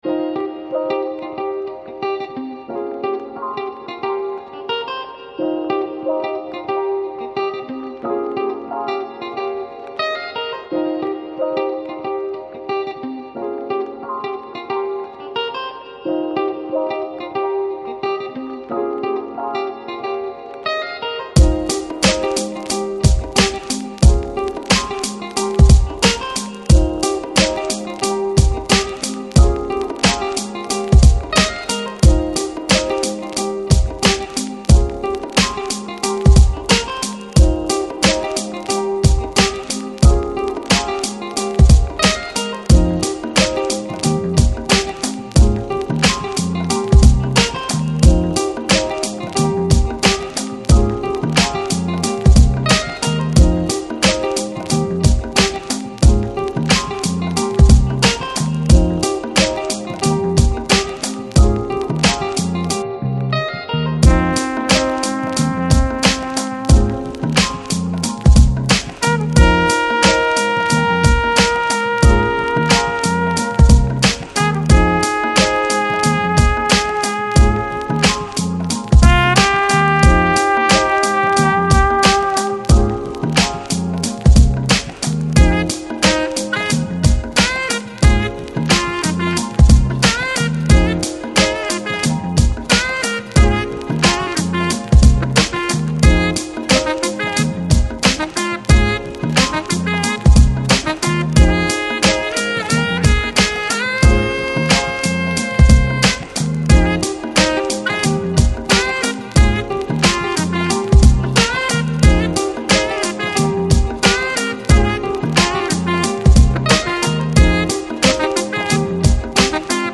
Lounge, Chill Out, Smooth Jazz, Nu Jazz